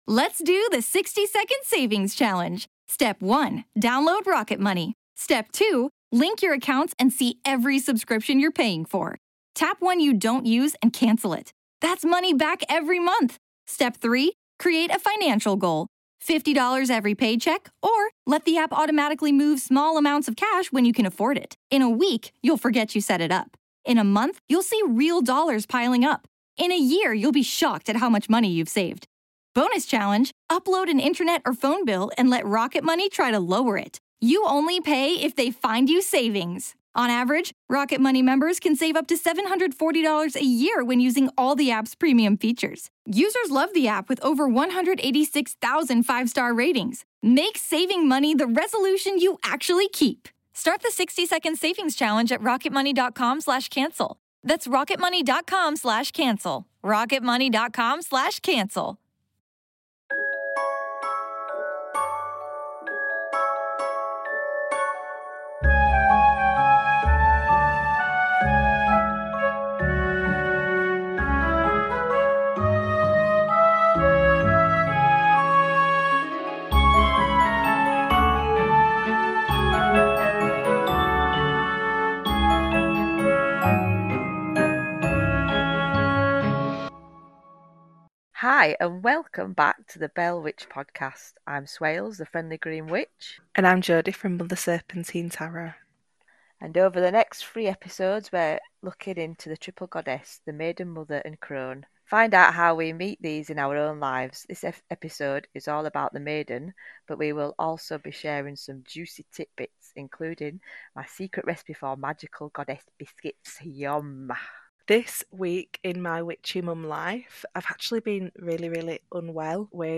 Pour yourself a brew or a glass of mead, come sit with us two northern birds and have a laugh as we go off on tangents. Expect laughter, soothing Yorkshire Mum sofa chat vibes...